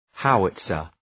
Προφορά
{‘haʋıtsər}